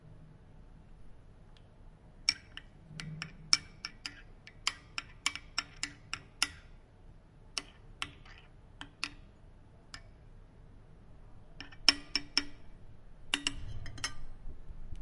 描述：Zamícháníšálkukávy
Tag: 咖啡 咖啡